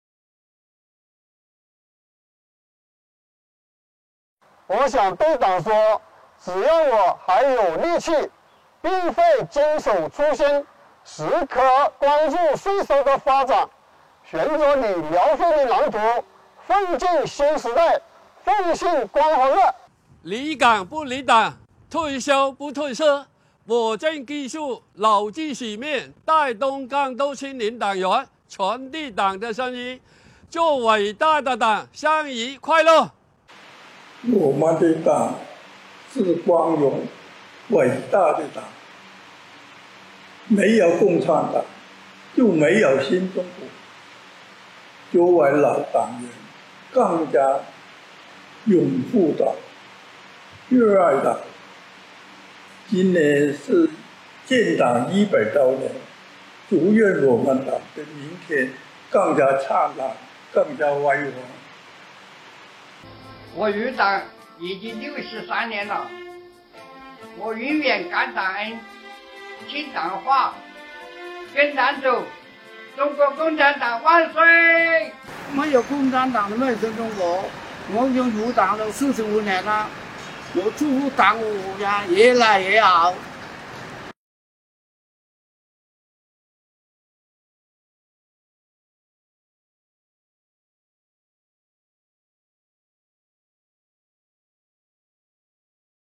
广西税务的党员同志对党的感恩和祝福！